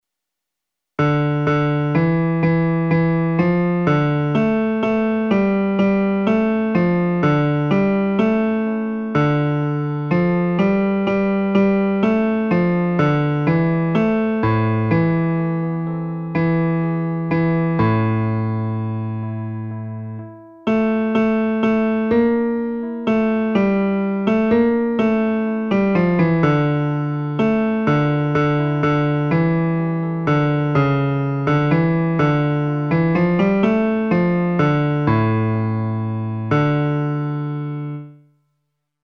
Bajo